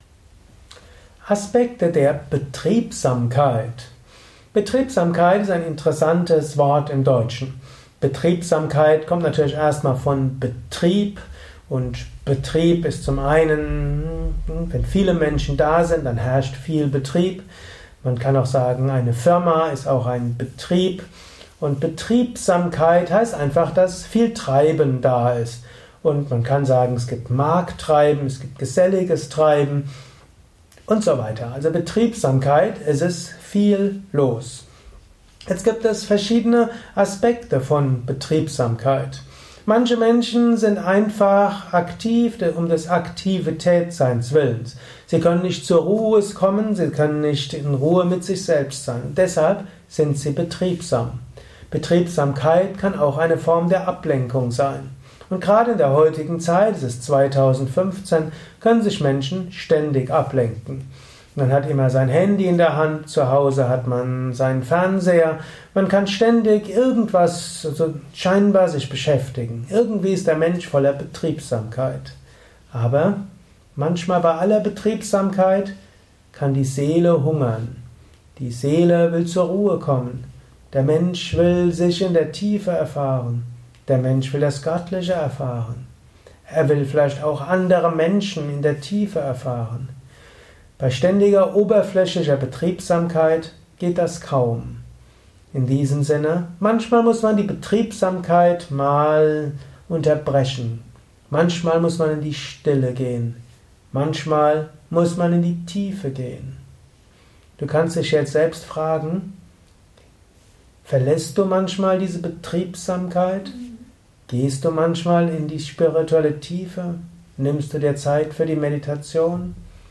Eine Abhandlung mit Inhalt Betriebsamkeit. Erfahre einiges über Betriebsamkeit in einem kurzen Spontan-Audiovortrag.